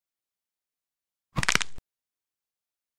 جلوه های صوتی
دانلود صدای شکستن استخوان یا کمر 1 از ساعد نیوز با لینک مستقیم و کیفیت بالا